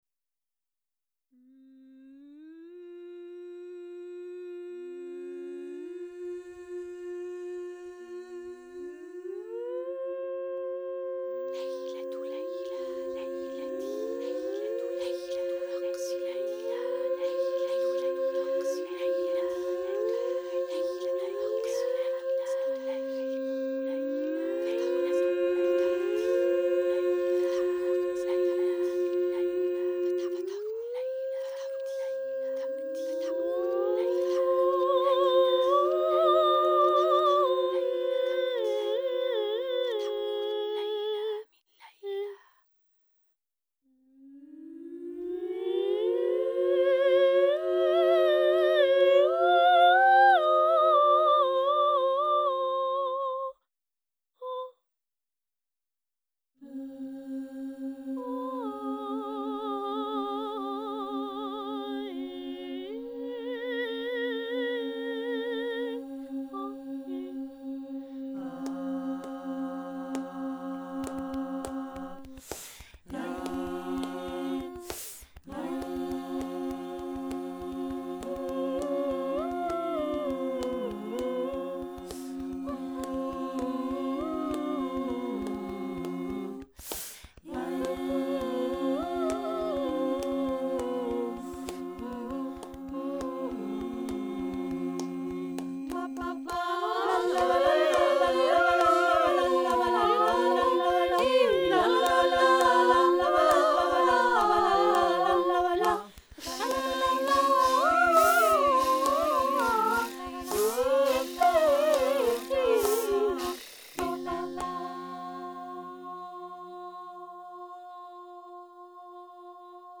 9 female voices
In the piece we find modal language and body percussion.
Subtitle Oriental dance for 9 female voices
Recording of the premiere